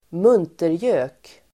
muntergök substantiv (vardagligt), cheerful fellow [informal]Uttal: [²m'un:terjö:k] Böjningar: muntergöken, muntergökarDefinition: lustigkurre